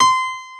AcousticPiano(5)_C6_22k.wav